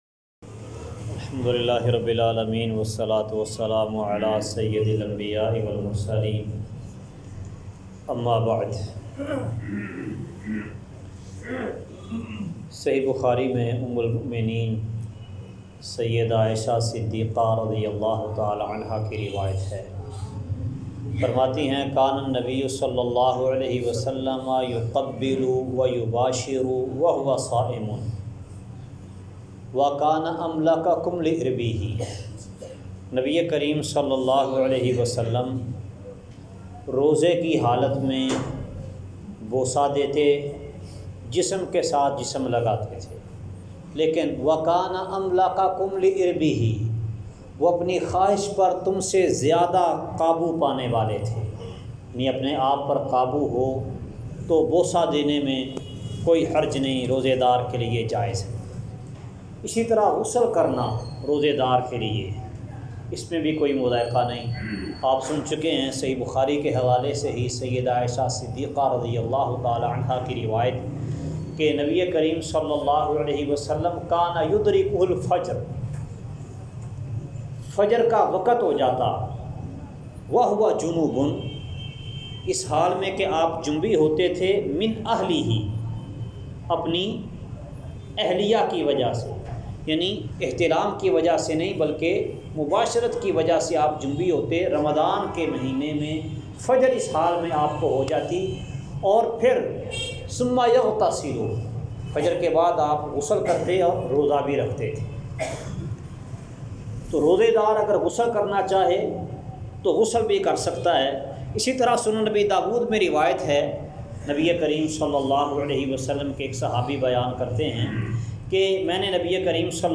روزے میں جائز کام درس کا خلاصہ ایسے کام جو روزہ دار کے لیے جائز ہیں آڈیو فائل ڈاؤنلوڈ کریں × الحمد لله رب العالمين، والصلاة والسلام على سيد الأنبياء والمرسلين، أما بعد!